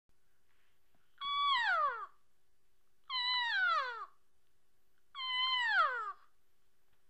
Cow Mew Sounds Mew Cow Elk make the same sounds as the calves. The difference is the lower pitch and longer duration than the calf sounds. The mew is a longer sound than a chirp.